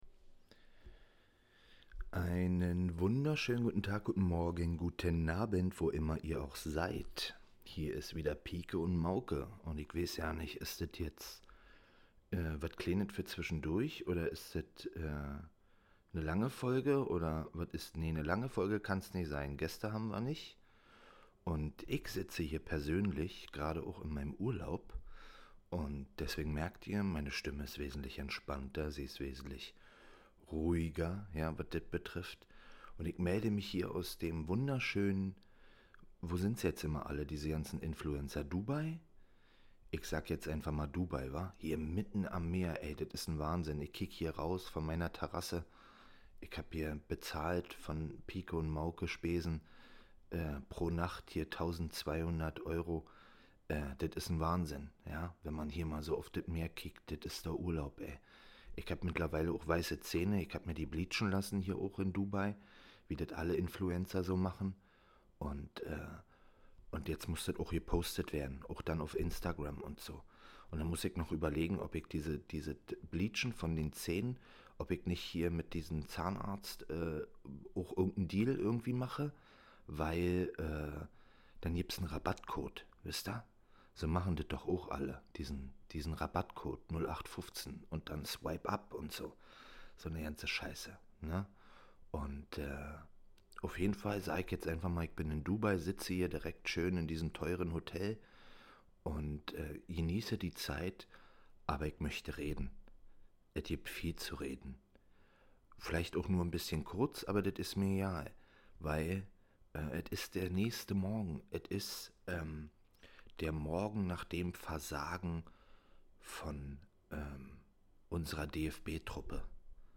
Live aus Dubai oder vielleicht von woanders...Talktime is angesagt.